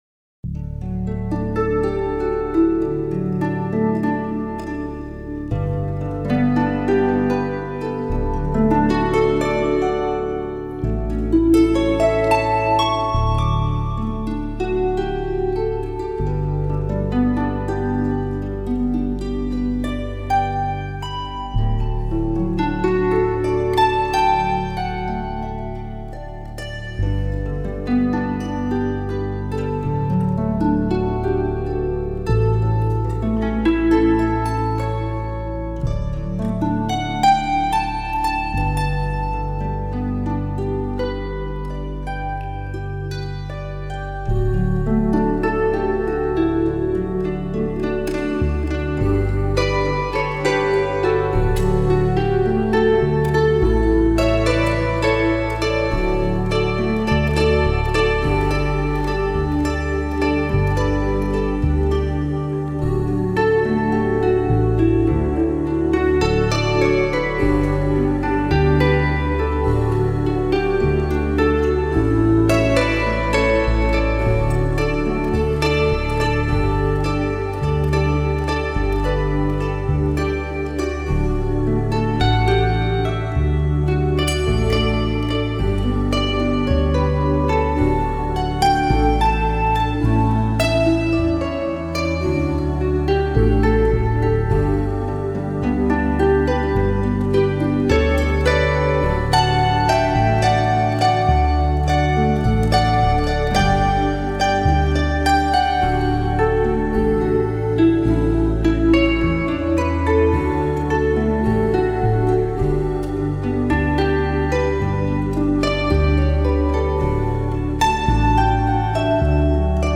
Жанр: Relax